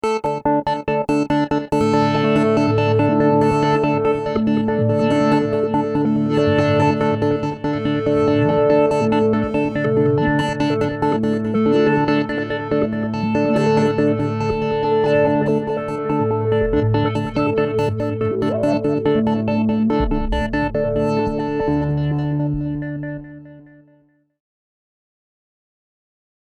- Long Spheric Ambient Pads -